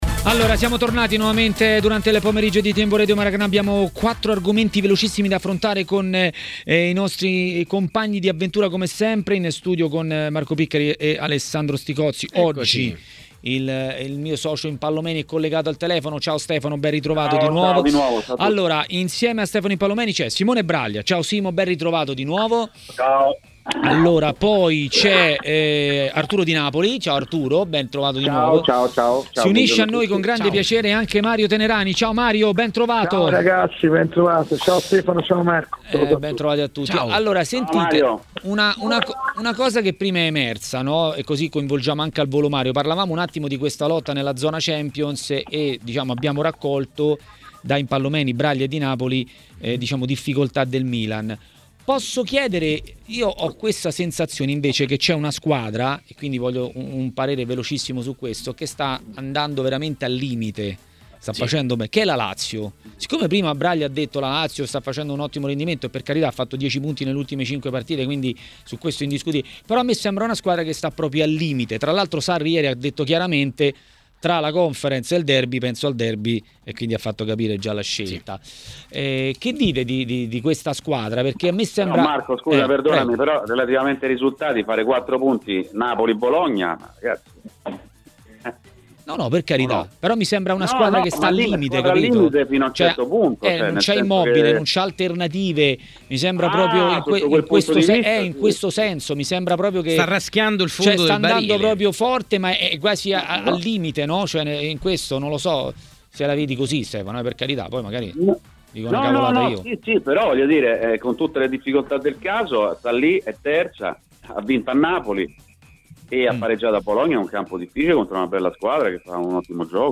A commentare i temi del giorno a Maracanà, nel pomeriggio di TMW Radio, è stato l'ex portiere Simone Braglia.